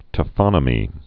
(tə-fŏnə-mē)